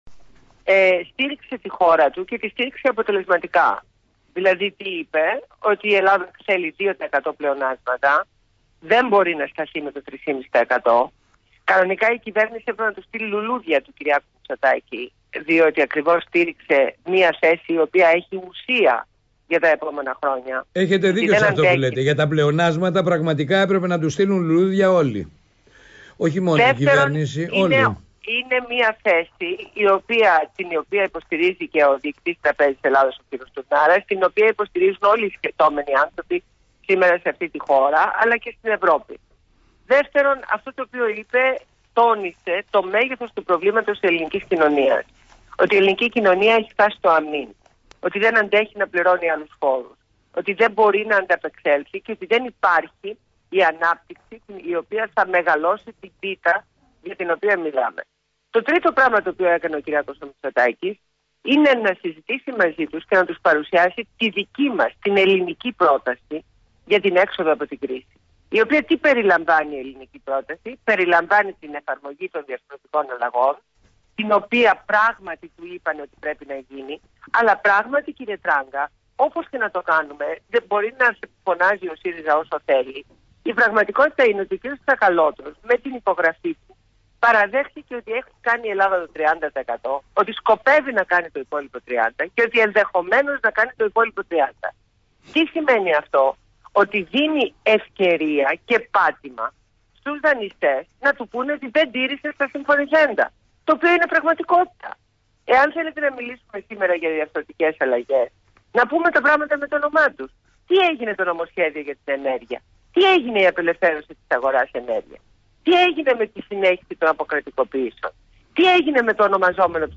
Ακούστε τη συνέντευξη στα Παραπολιτικά fm στο δημοσιογράφο Γ. Τράγκα